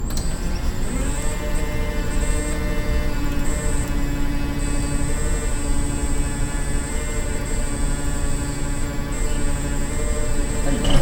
Pleasant Sound with Lower Vibration Levels
Gear Pump With Pleasant Comfortable Sound
In addition to the low noise and vibration of the SMG series, the SRP series is able to provide a pleasant and comfortable sound for operators.